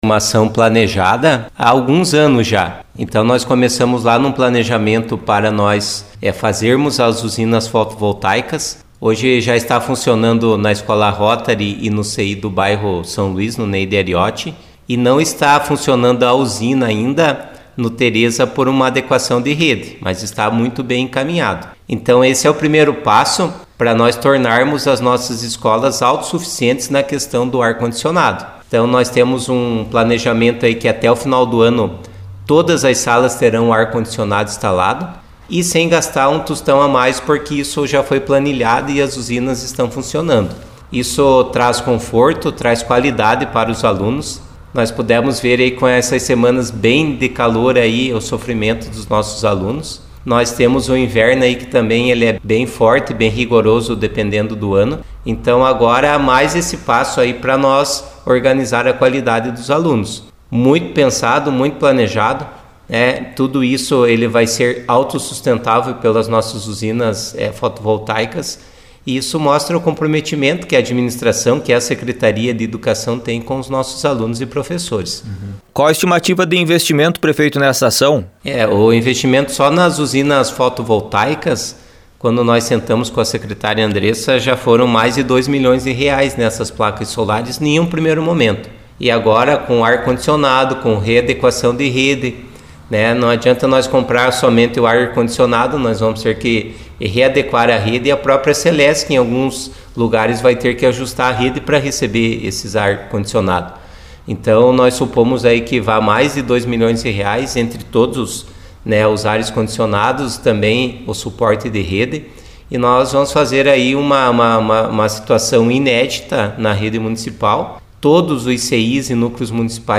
Em entrevista a nossa reportagem ele comentou sobre o investimento.